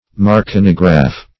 Search Result for " marconigraph" : The Collaborative International Dictionary of English v.0.48: Marconigraph \Mar*co"ni*graph\, n. [Marconi + -graph.] The apparatus used in Marconi wireless telegraphy.